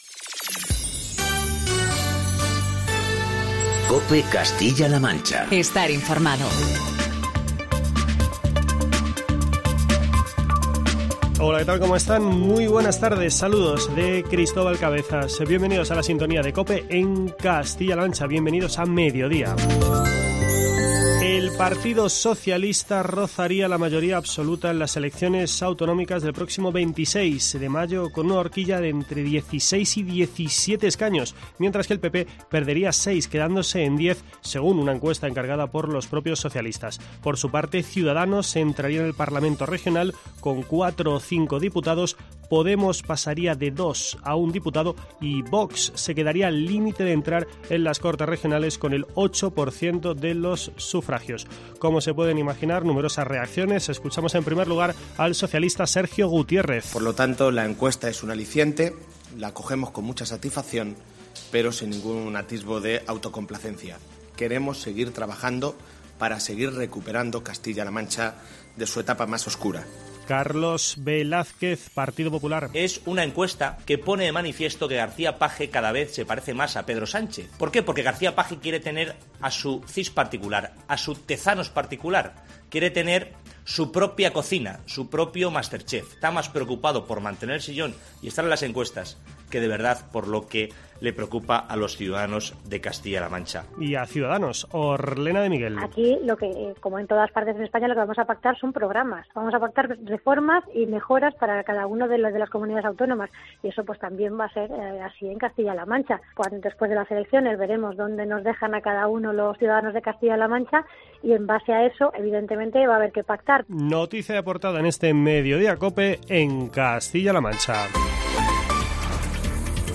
Así lo ha avanzado la consejera de Bienestar Social, Aurelia Sánchez, quien, en rueda de prensa, y tras detallar las novedades que incluye este decreto, que entrará en vigor a los 20 días de su publicación en el Diario Oficial de Castilla-La Mancha (DOCM), ha indicado que el Ejecutivo autonómico no contempla contratar a más personal para desarrollar dicho decreto, que se ejecutará con la mejora de los sistemas informáticos que agilizarán el proceso.